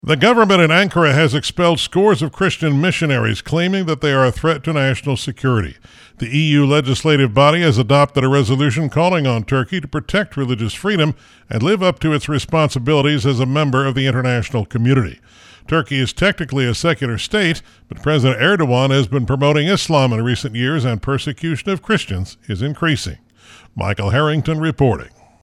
Latest News » Listen Now